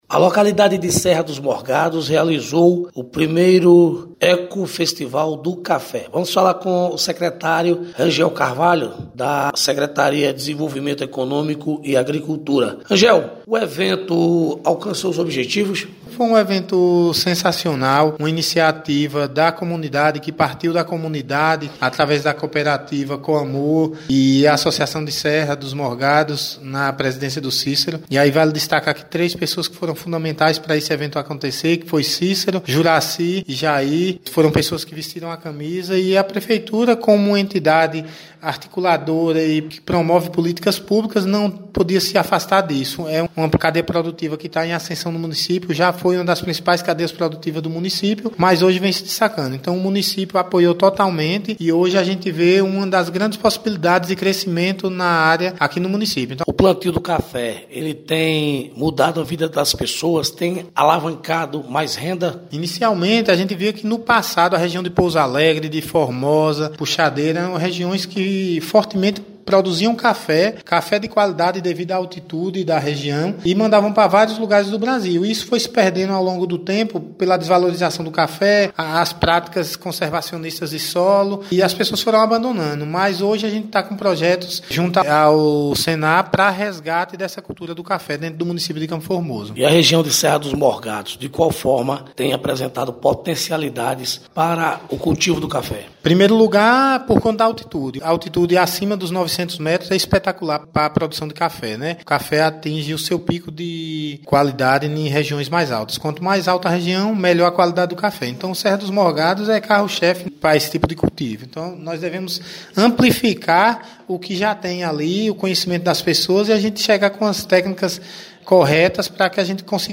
Secretário da agricultura Rangel Carvalho comenta sobre o 1º Ecofestival do Café que aconteceu na Serra dos Morgados